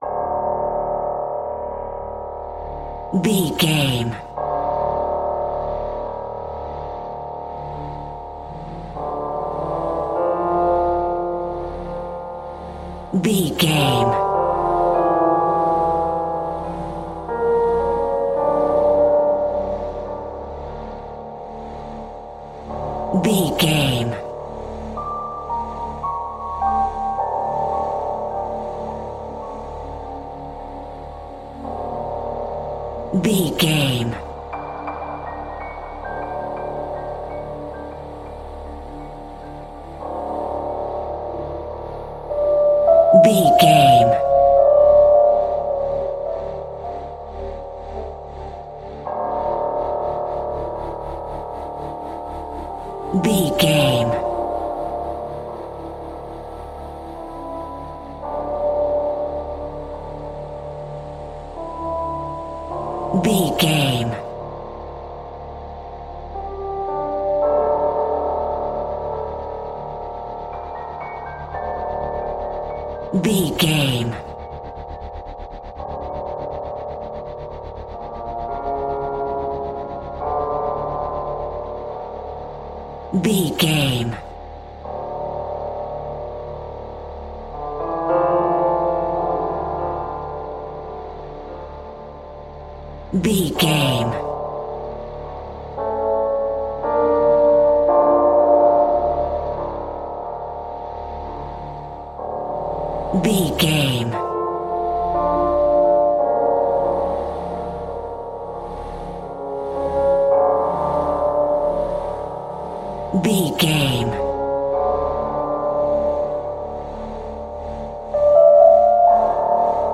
Aeolian/Minor
D
tension
ominous
eerie
piano
synth
ambience
pads